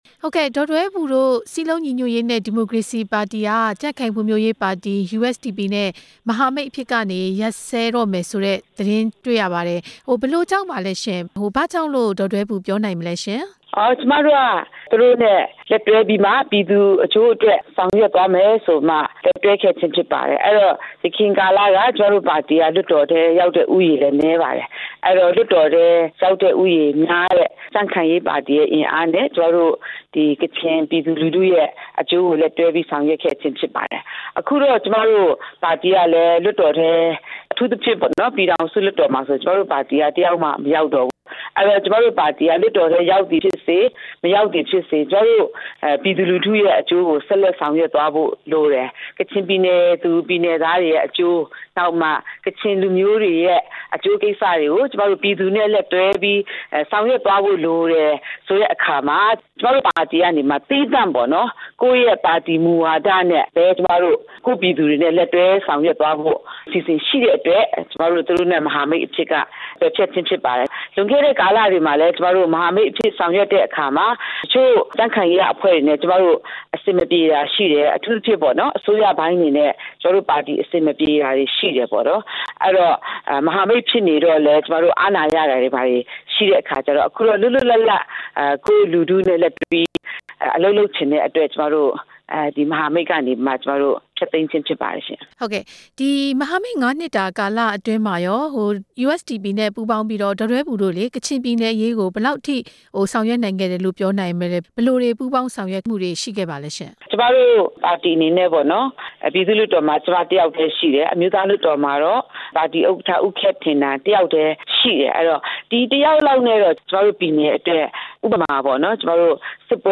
ပြည်သူ့လွှတ်တော်ကိုယ်စားလှယ်ဟောင်း ဒေါ်ဒွဲဘူနဲ့ မေးမြန်းချက်